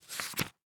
ES_Book Paperback 16 - SFX Producer.wav